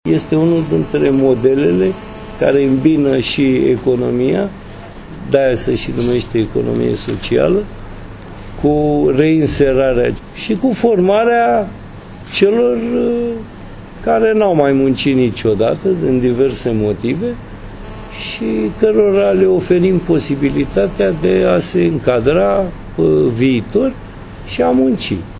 Primarul Capitalei, Sorin Oprescu explică rolul acestor proiecte: